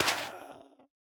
Minecraft Version Minecraft Version 1.21.5 Latest Release | Latest Snapshot 1.21.5 / assets / minecraft / sounds / block / soul_sand / break8.ogg Compare With Compare With Latest Release | Latest Snapshot